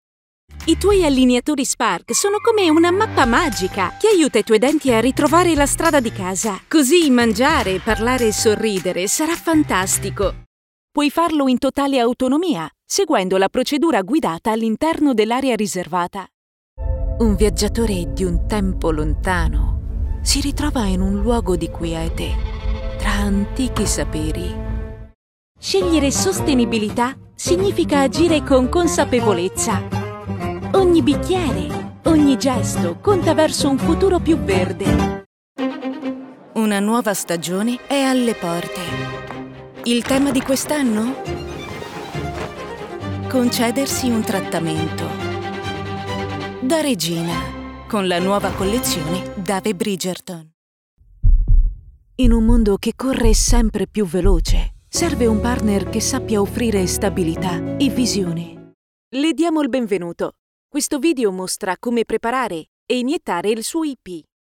Hire Professional Female Voice Over Talent
Yng Adult (18-29) | Adult (30-50)